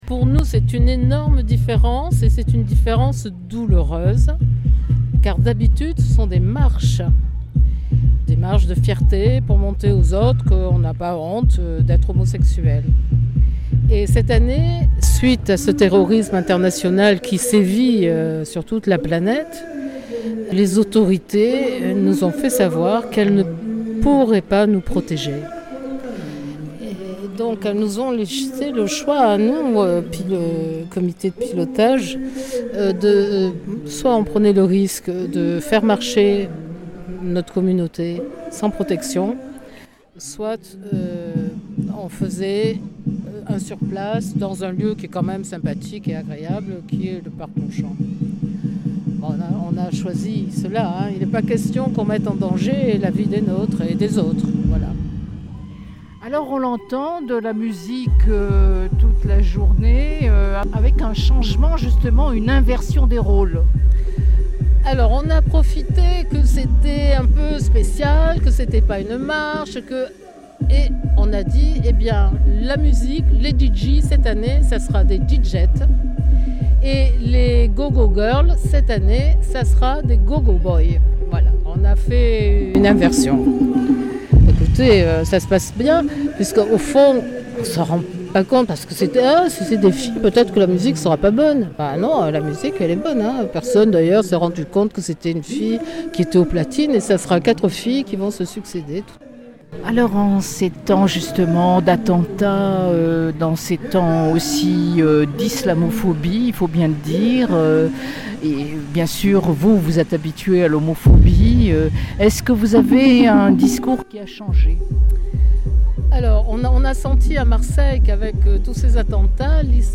Pride Marseille, annulée en juillet car programmée tout juste après l’attentat de Nice le 14 juillet, s’est finalement tenue, ce samedi 3 septembre, dans l’enclos du Parc Longchamp, avec fouilles aux deux entrées.